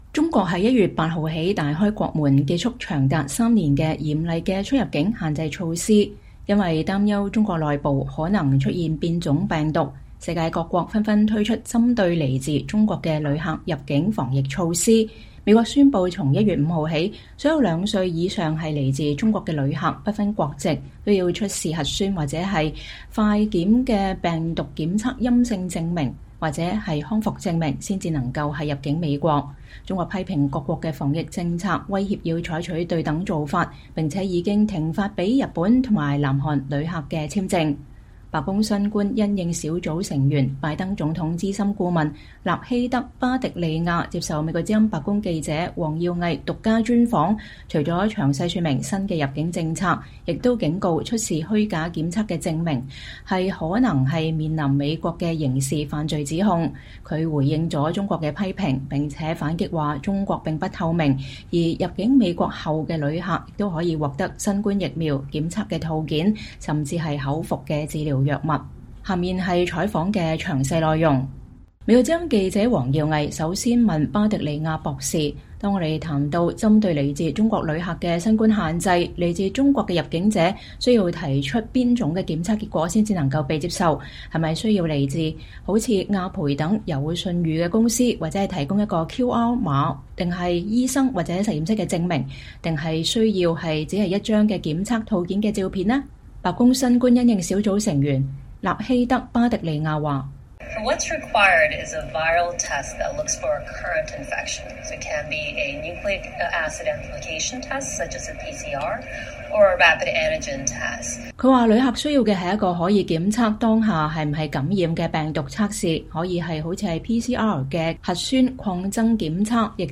VOA獨家專訪白宮新冠小組官員: 中國疫情不透明令美國推防疫新規，假檢測或遭刑事犯罪指控